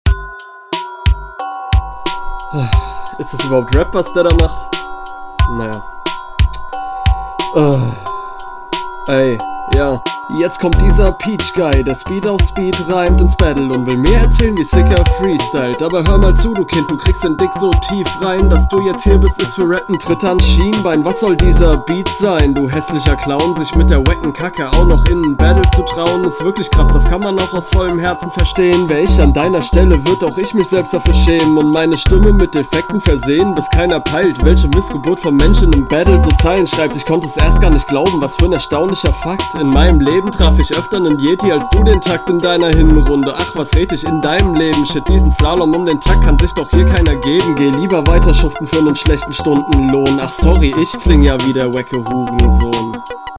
Ach ja, du bist der Typ mit der deutschesten Stimme jemals.
dope, bisschen mehr druck in der stimme wäre nice